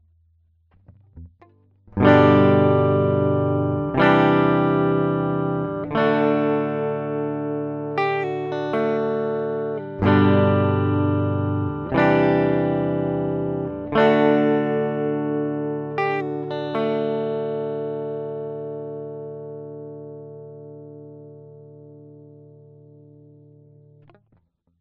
In der Röhrenvorstufe hingegen klingt alles etwas runder und wärmer, der Attack wirkt wie etwas »verschmiert«.
Aufgenommen habe ich der Einfachheit halber einmal meine eigene Stimme mit Sprache und dann eine Gibson Les Paul, die mit Pickups vom Typ Burstbucker Pro bestückt ist.
Klangbeispiele mit Gitarre